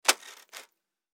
دانلود آهنگ دوربین 12 از افکت صوتی اشیاء
دانلود صدای دوربین 12 از ساعد نیوز با لینک مستقیم و کیفیت بالا
جلوه های صوتی